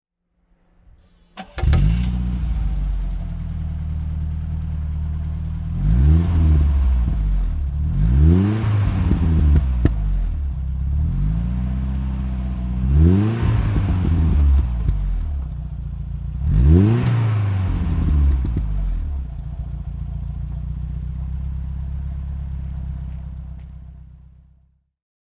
BMW 2002 turbo (1974) - Starten und Leerlauf